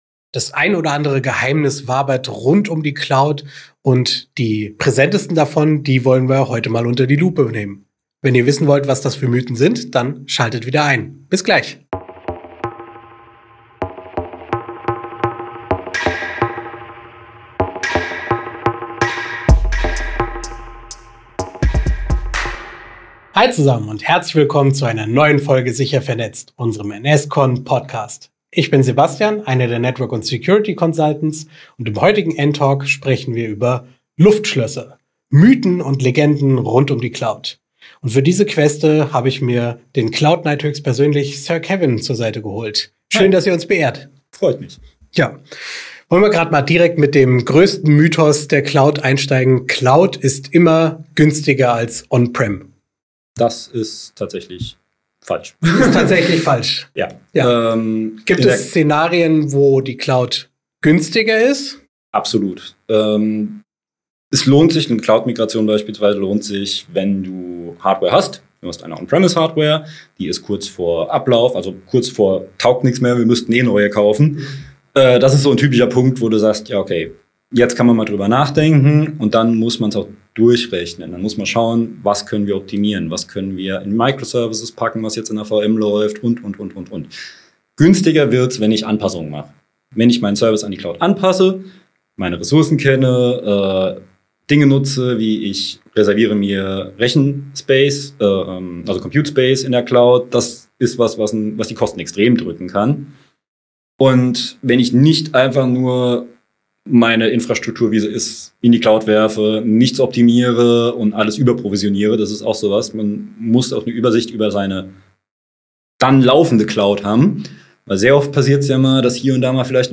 Cloud-Mythen entlarvt: Sicherheit, Kosten und Skalierung im Experten-Talk